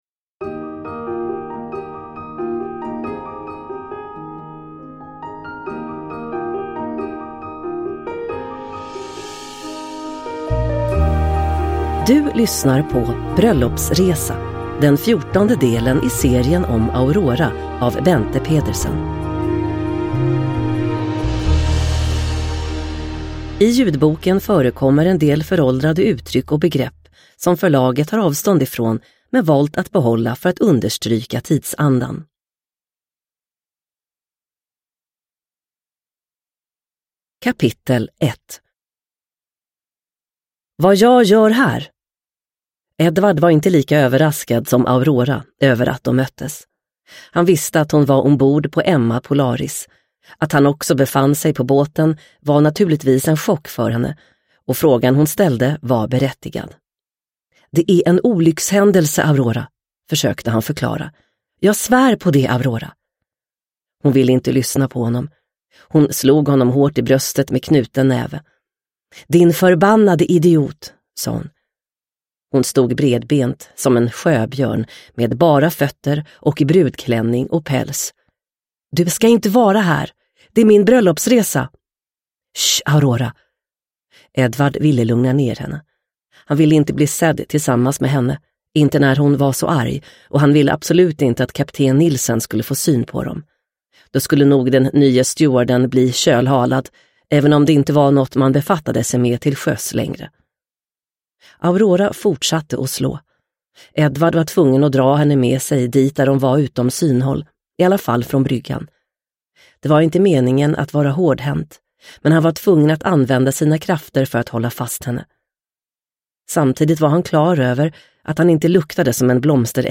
Bröllopsresa – Ljudbok – Laddas ner